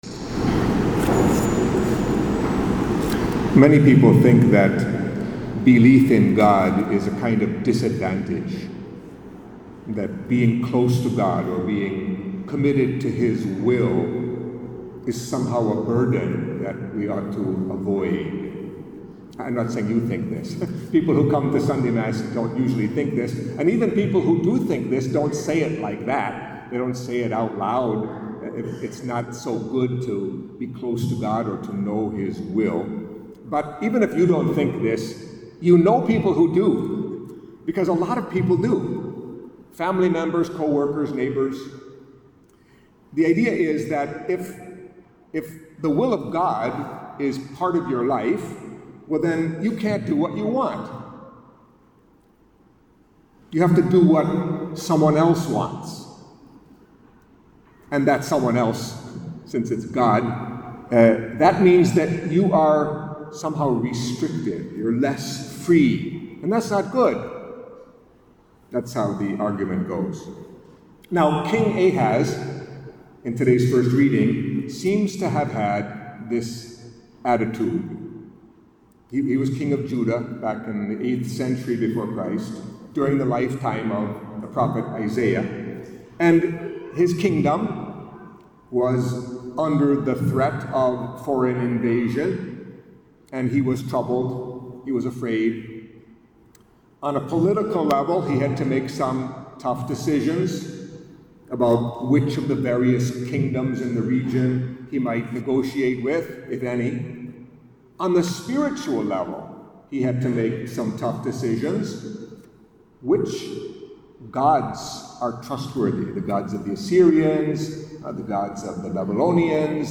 Catholic Mass homily for the Fourth Sunday of Advent